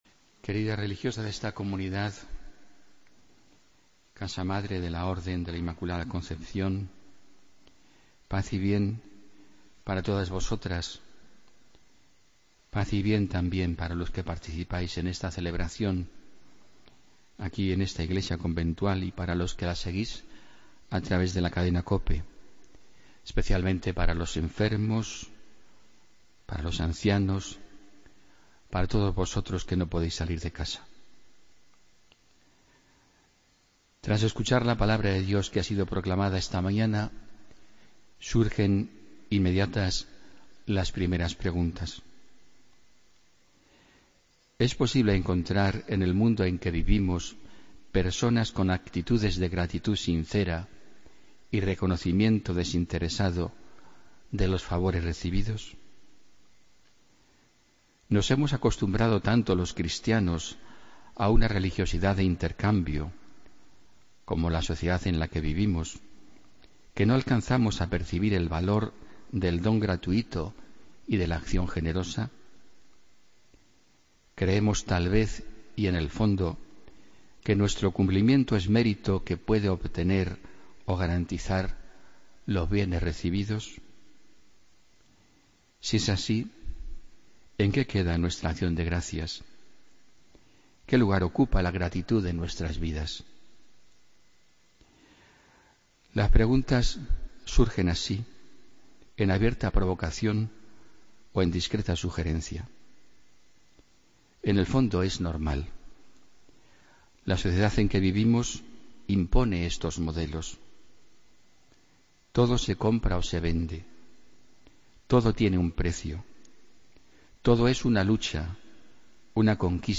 Homilía del domingo 9 de octubre de 2016